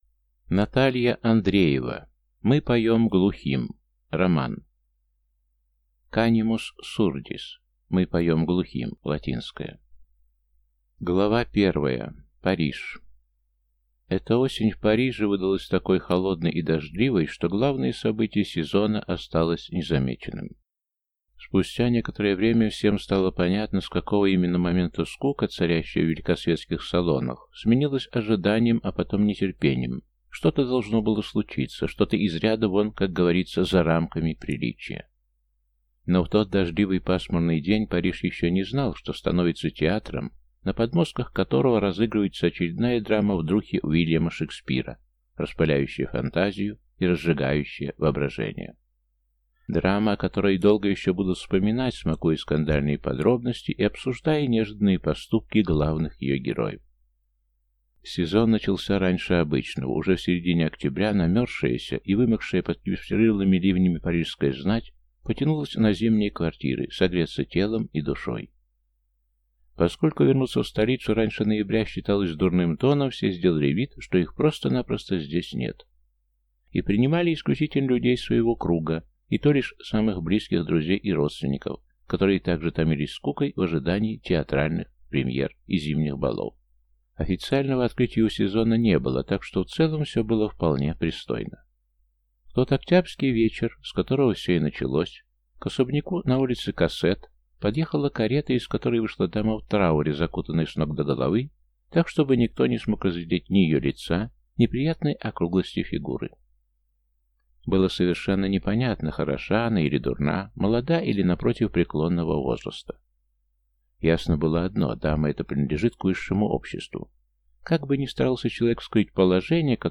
Аудиокнига Мы поем глухим | Библиотека аудиокниг
Прослушать и бесплатно скачать фрагмент аудиокниги